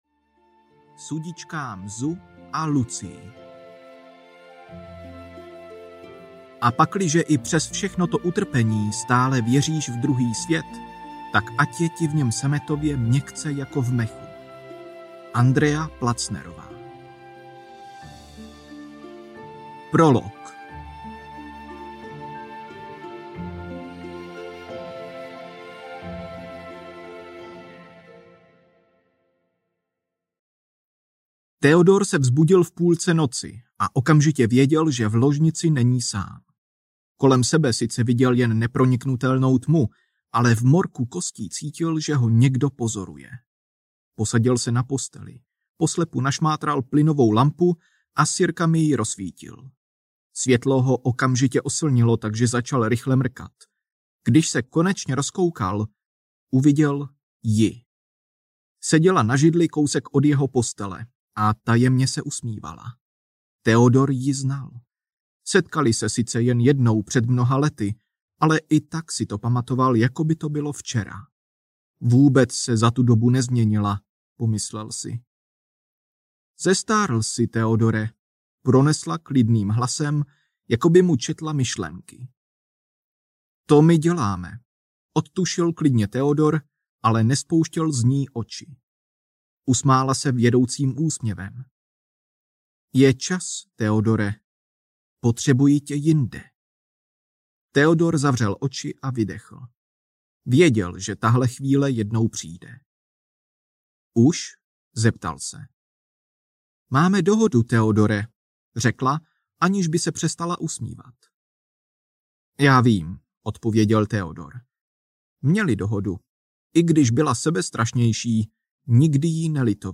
Ve zdech audiokniha
Ukázka z knihy